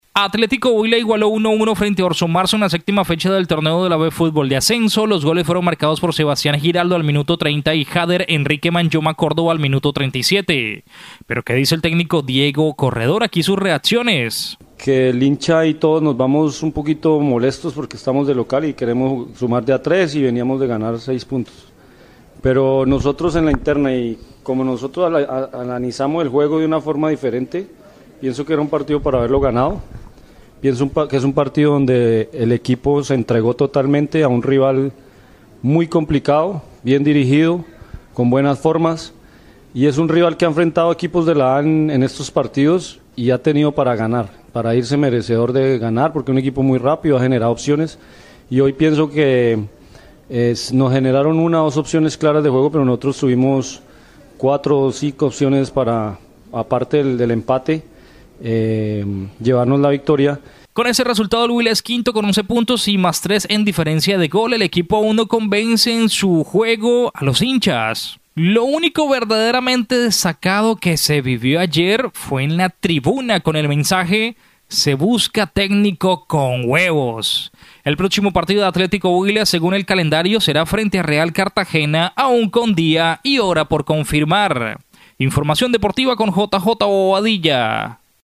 VOZ_TITULAR_DEPORTES_17_MARZO.MP3